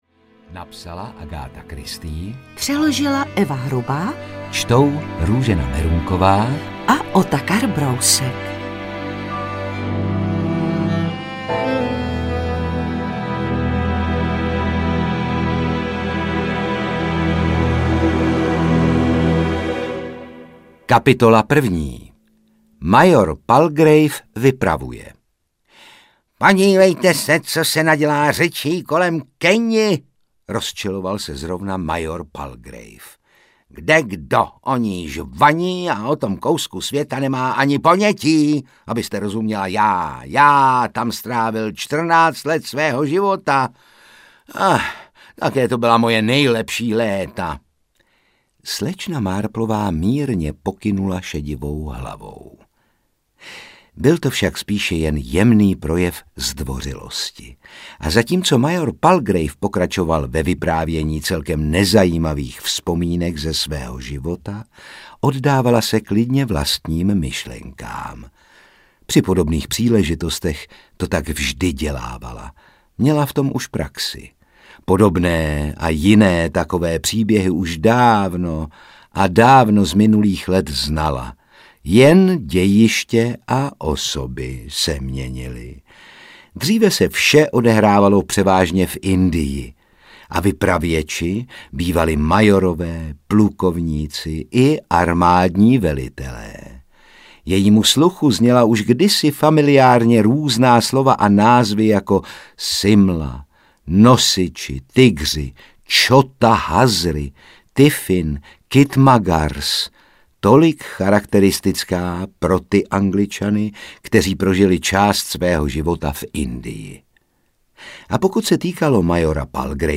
Karibské tajemství audiokniha
Ukázka z knihy
• InterpretRůžena Merunková, Otakar Brousek ml.